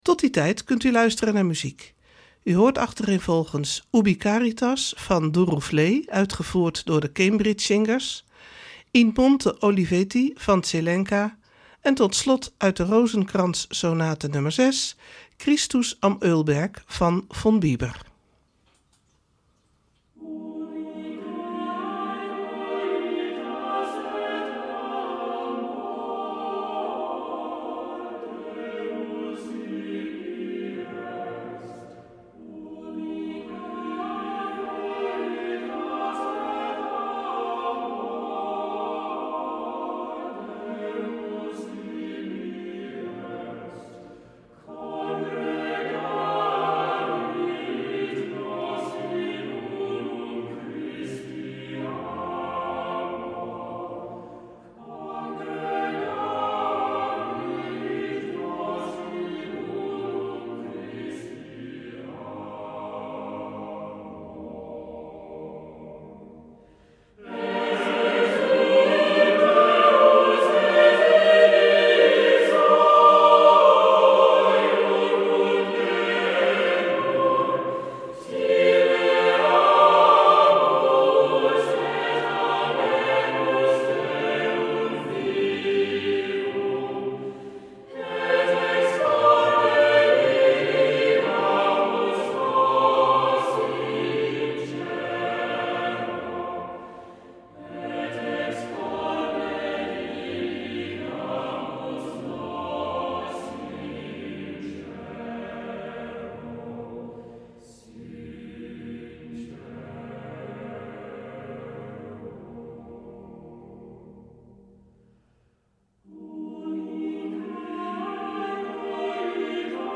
Opening van deze Witte Donderdag met muziek, rechtstreeks vanuit onze studio.